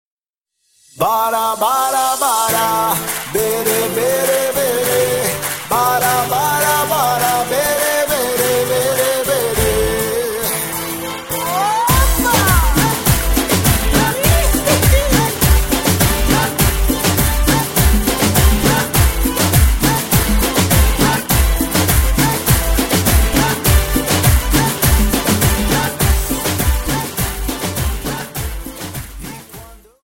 Samba 51 Song